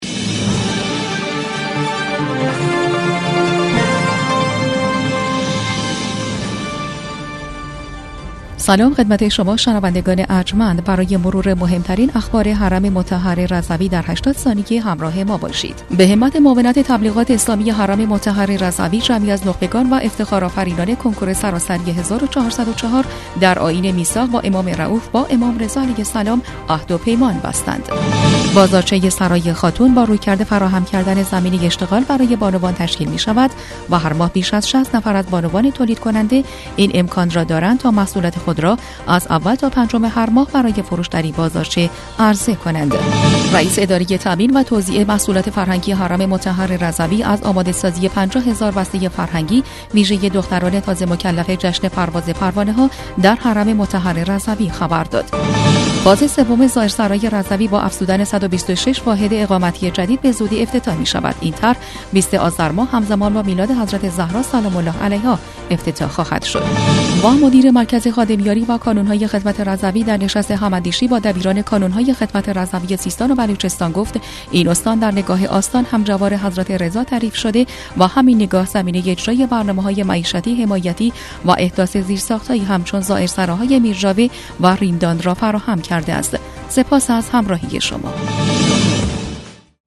برچسب ها: صوت رادیو رضوی بسته خبری رادیو رضوی